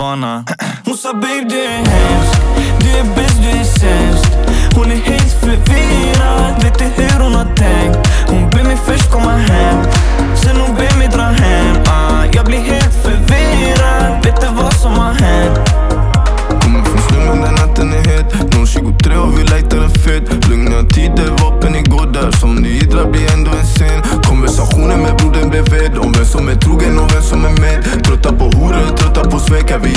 Жанр: Рэп и хип-хоп / Иностранный рэп и хип-хоп
# Hip-Hop